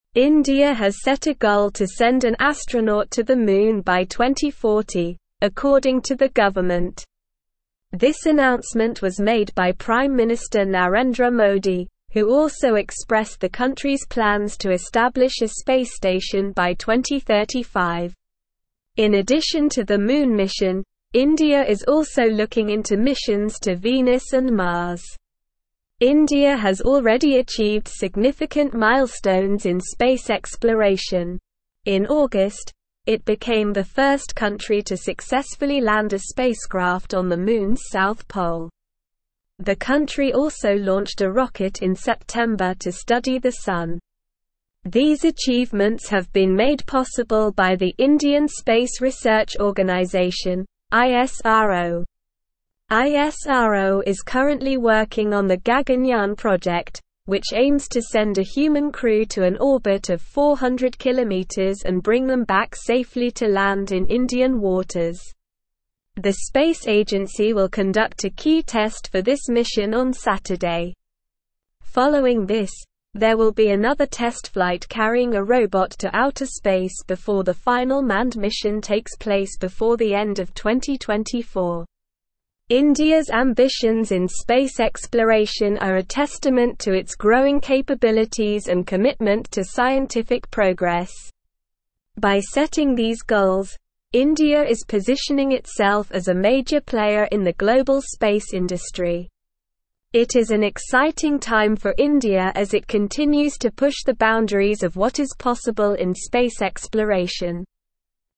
Slow
English-Newsroom-Advanced-SLOW-Reading-Indias-Ambitious-Space-Goals-Moon-Mars-and-More.mp3